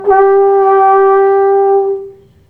Sons cors des Alpes
SONS ET LOOPS DE CORS DES ALPES
Banque sons : INSTRUMENTS A VENT